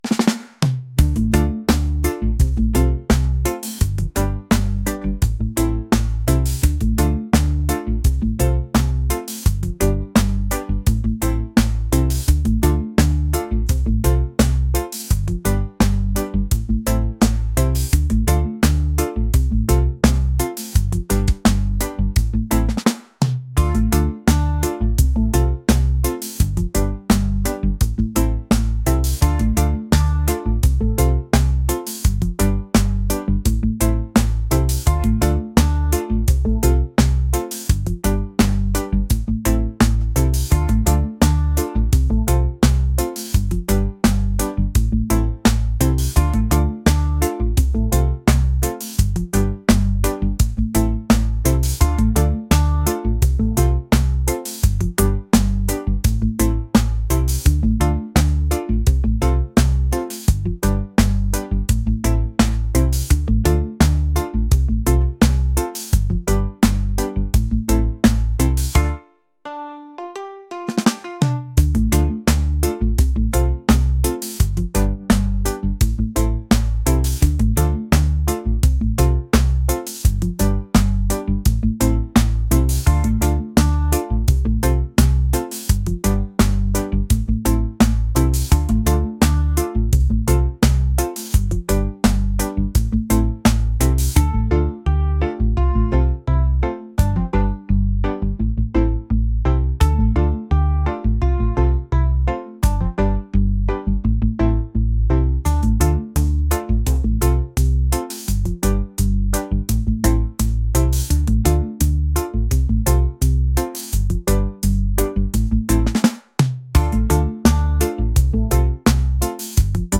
reggae | lounge | lofi & chill beats